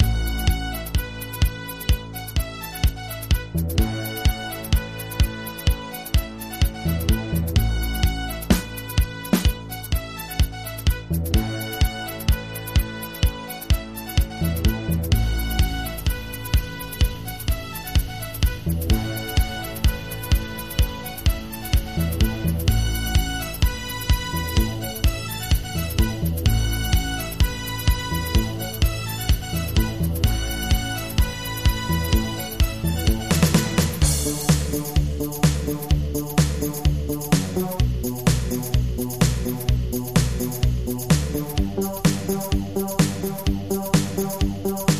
Descarga el midi/karaoke